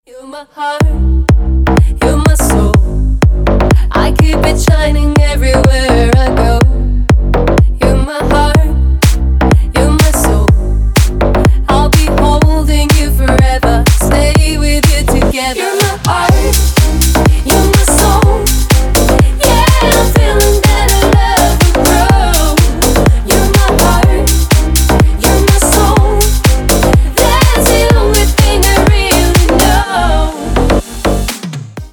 Dance рингтоны
Танцевальный ремикс на вызов